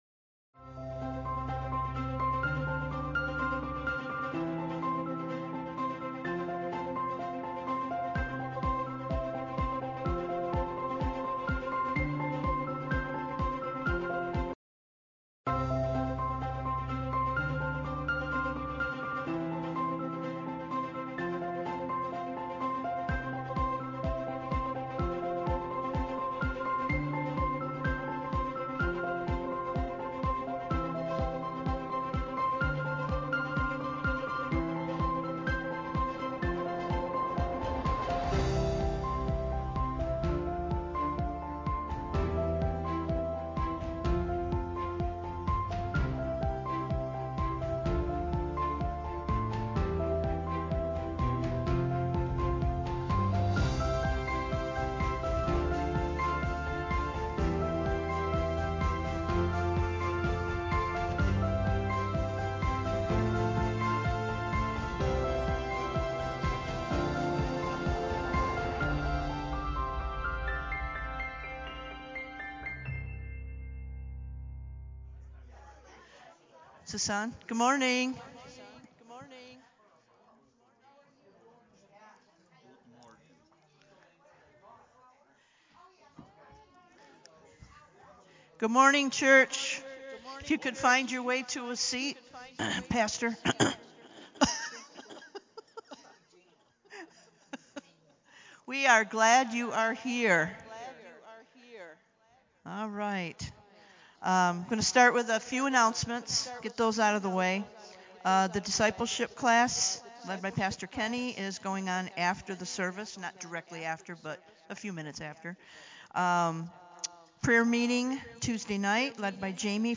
Faith – Purpose Sermon
Faith-Purpose-Sermon-Audio-CD.mp3